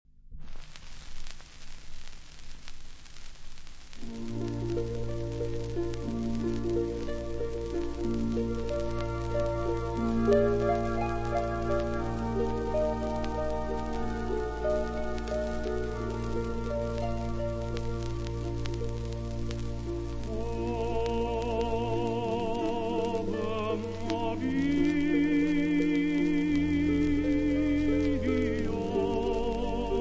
• Gigli, Beniamino [interprete]
• arie
• registrazione sonora di musica